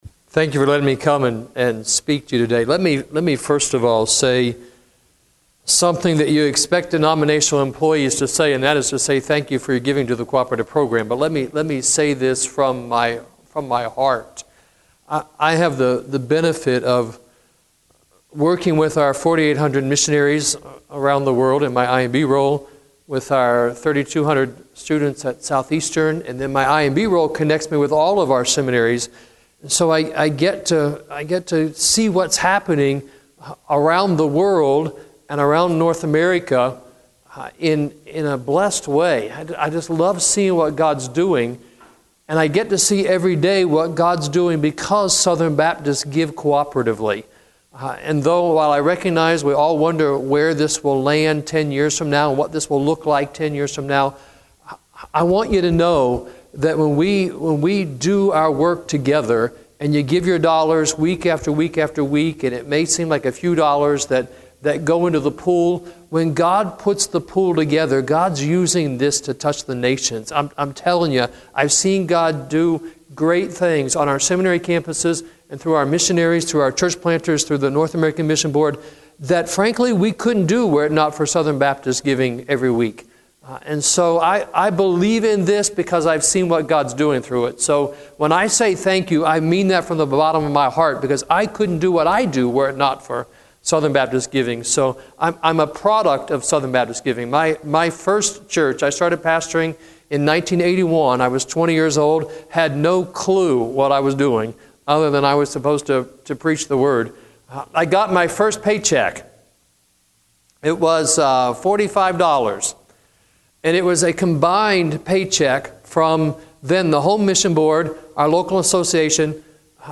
Address: Helping Your Church in Evangelism and Missions Recording Date